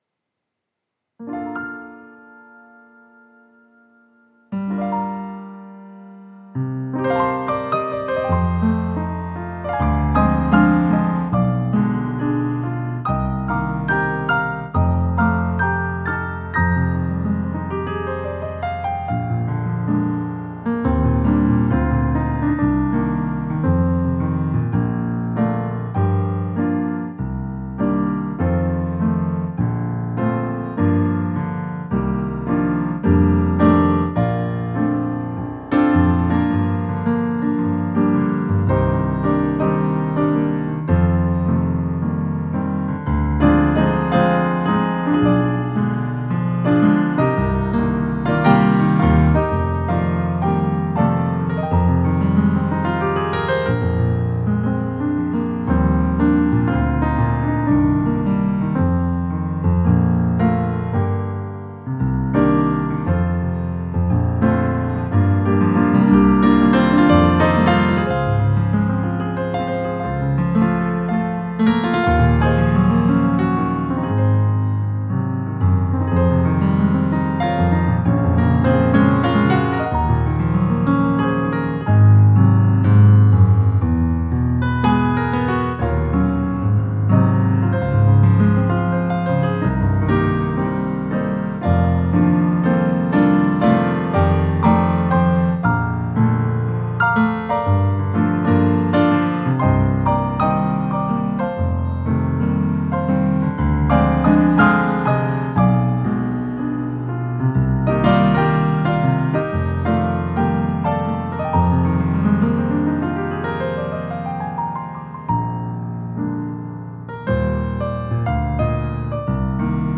Acoustic Piano Cover